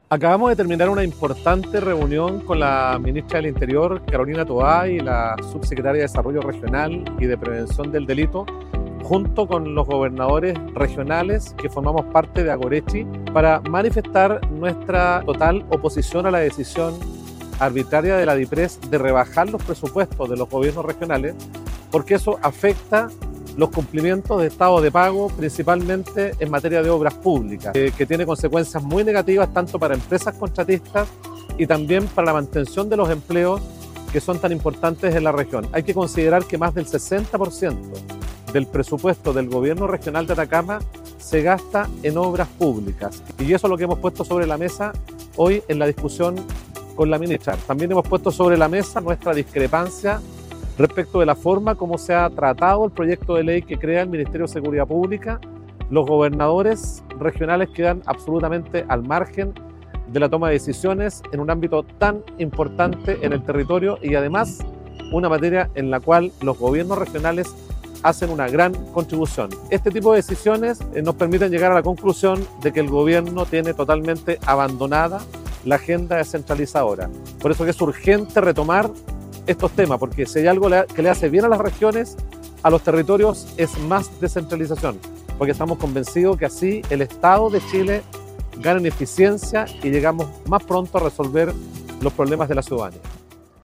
GOBERNADOR-MIGUEL-VARGAS-1.mp3